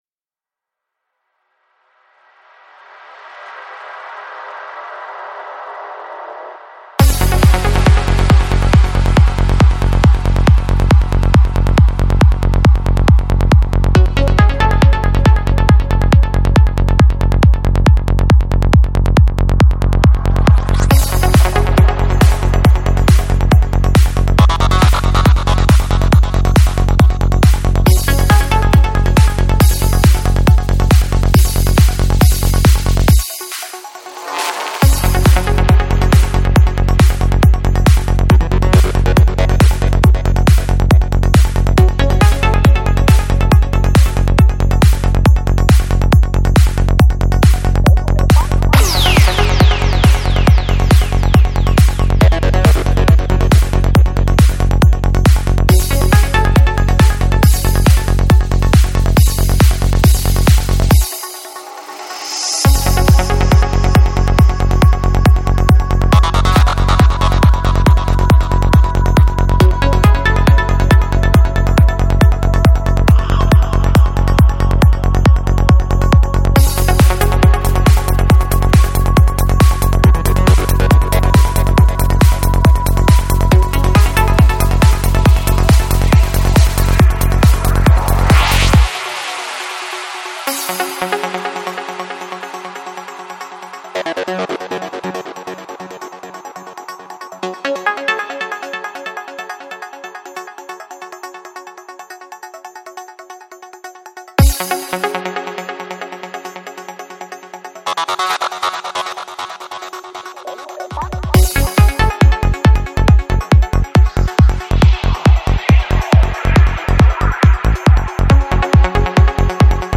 Жанр: Psychedelic